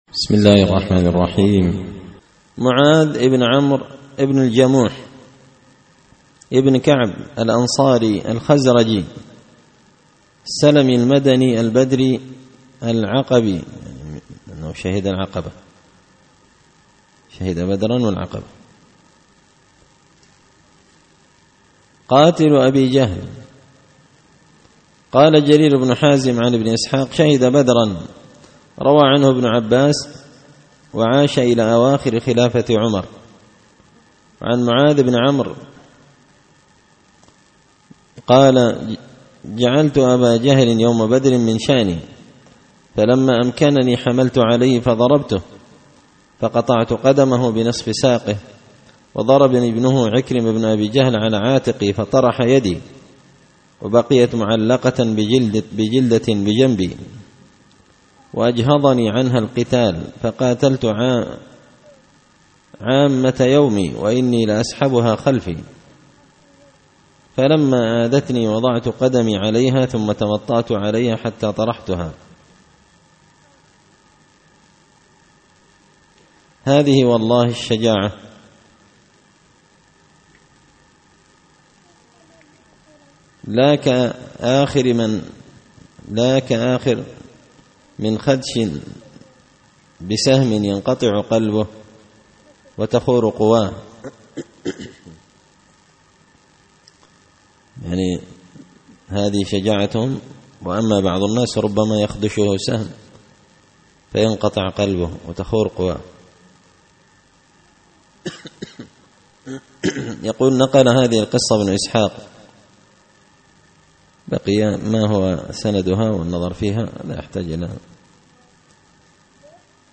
الدرس 32معاذ ابن عمرو ابن الجموح
دار الحديث بمسجد الفرقان ـ قشن ـ المهرة ـ اليمن